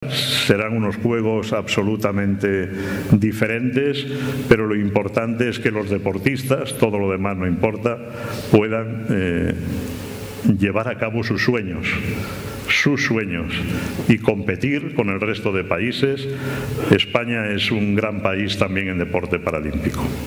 en el transcurso de un desayuno informativo organizado por Nueva Economía Forum